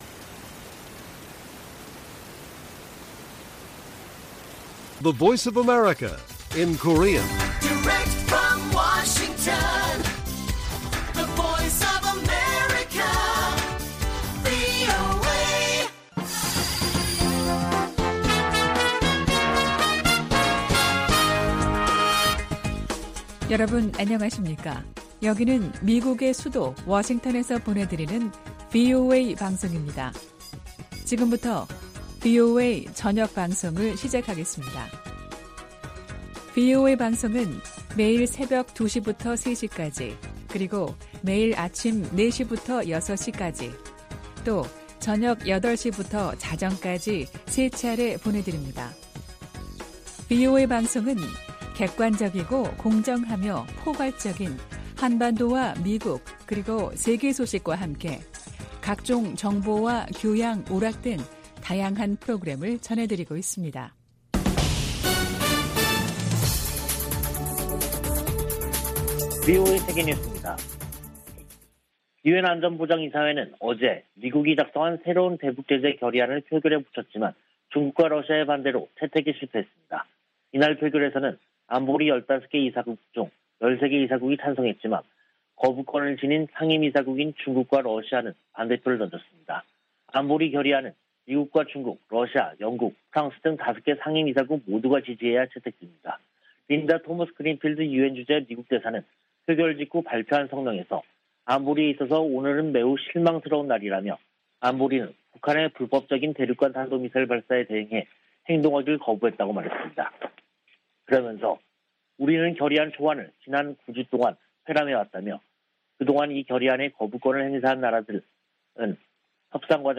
VOA 한국어 간판 뉴스 프로그램 '뉴스 투데이', 2022년 5월 27일 1부 방송입니다. 유엔 안보리가 중국과 러시아의 반대로 새 대북 결의안 채택에 실패했습니다. 토니 블링컨 미 국무장관은 대중국 전략을 공개하면서 북한 핵 문제를 상호 ‘협력 분야’로 꼽았습니다. 미 국무부가 올해 초 제재한 북한 국적자 등의 이름을 연방관보에 게시했습니다.